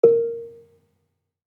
Gambang-A3-f.wav